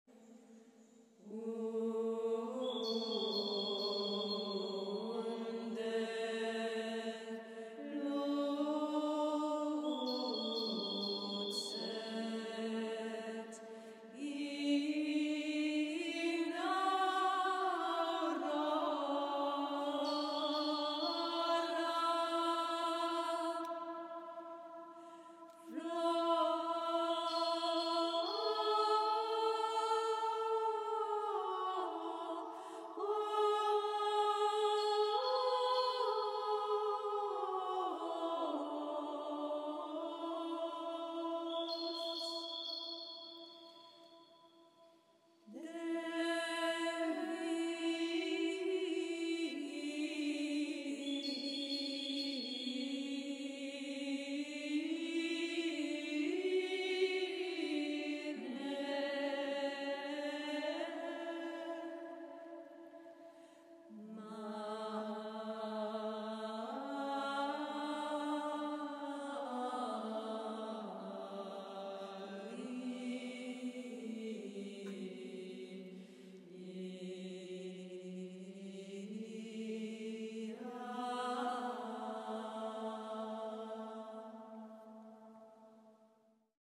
medieval chant